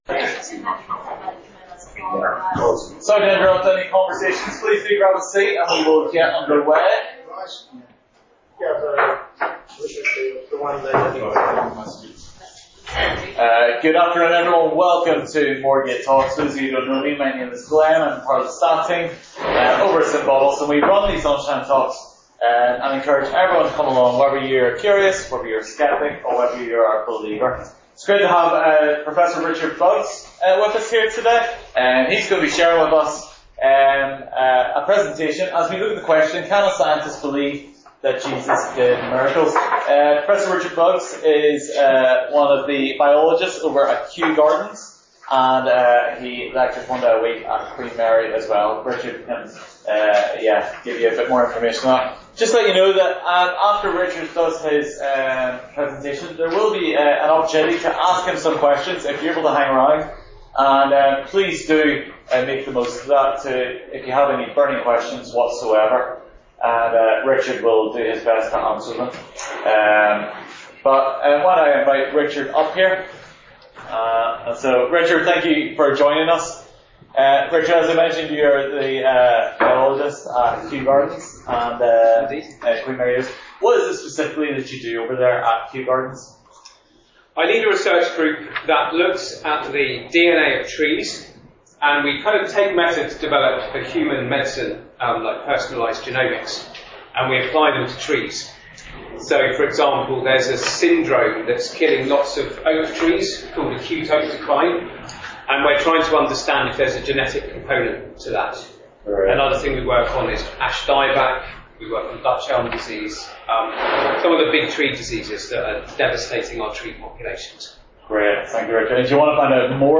Guest Interviews : Can a scientist believe Jesus did miracles?